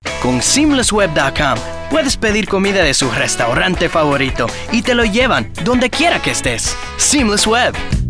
Adult, Young Adult
Has Own Studio
standard us
commercial
cool